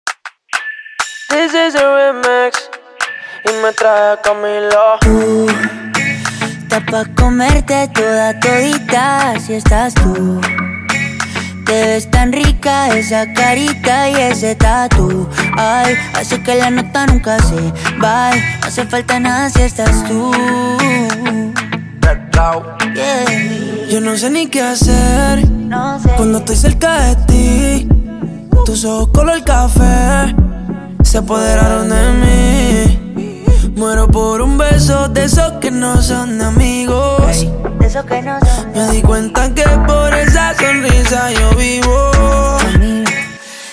MUSICA LATINA